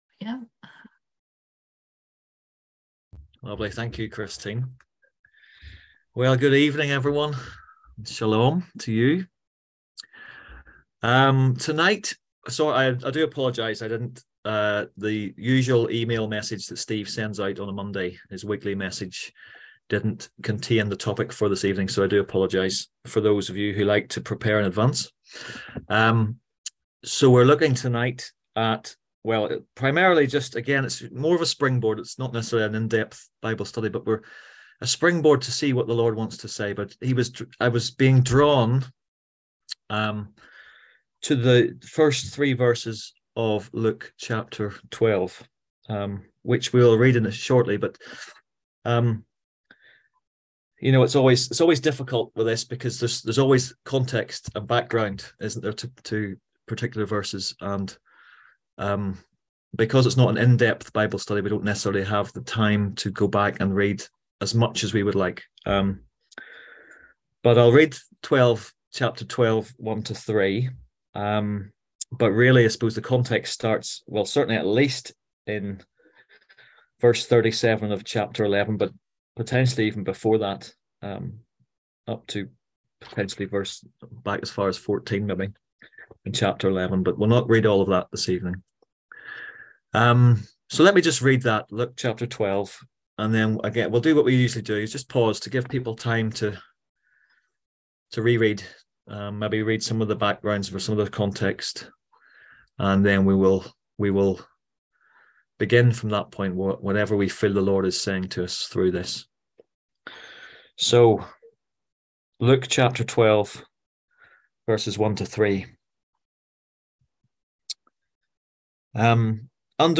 On March 13th at 7pm – 8:30pm on ZOOM ASK A QUESTION – Our lively discussion forum.
On March 13th at 7pm – 8:30pm on ZOOM